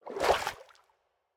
Minecraft Version Minecraft Version latest Latest Release | Latest Snapshot latest / assets / minecraft / sounds / mob / turtle / swim / swim2.ogg Compare With Compare With Latest Release | Latest Snapshot
swim2.ogg